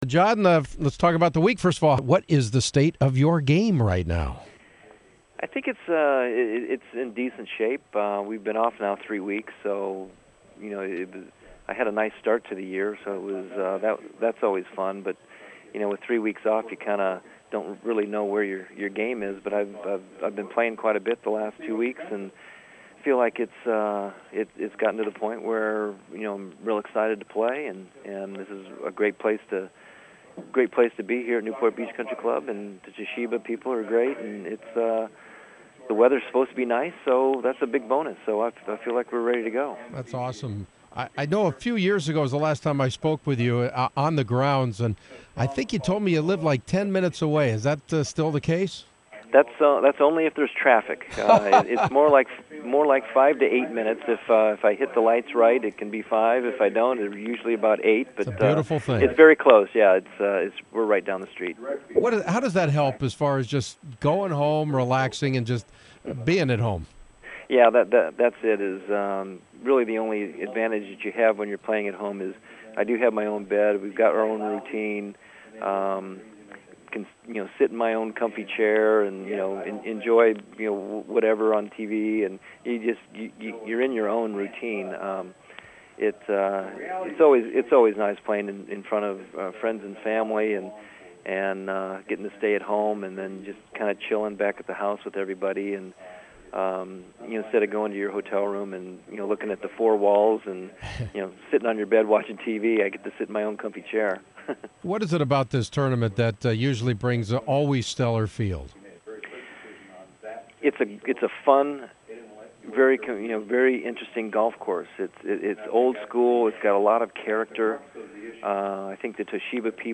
John was kind enough to call me this morning to discuss this week’s event along with covering a few current issues in the world of golf like the controversial new ruling of the long putter and it’s soon to be illegal anchoring to the body. And we chatted about his longtime buddy Tiger Woods who’s constant swing changing would ruin most careers but somehow not Tiger’s. Few know Tiger like Cook and it was great to get his insight on what Woods has in front of him to try and catch or surpass Jack Nicklaus’ major wins record of 18.